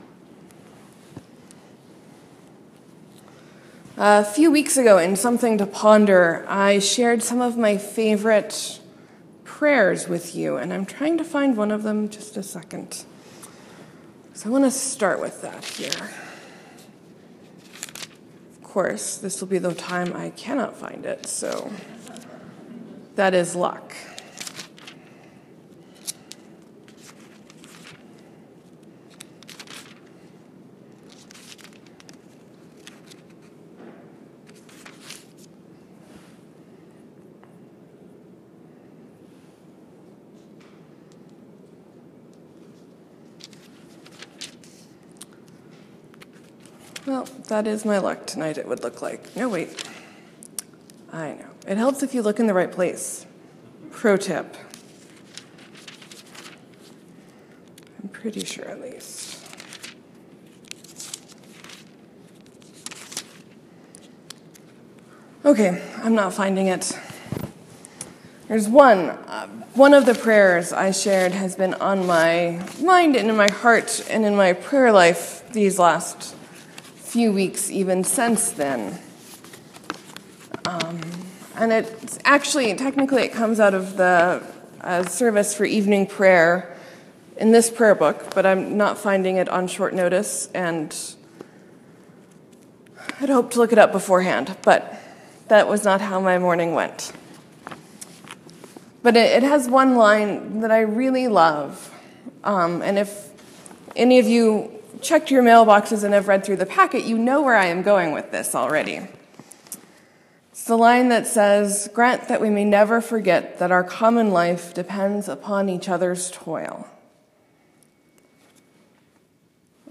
Sermon: This is a tough parable Jesus offers us this week.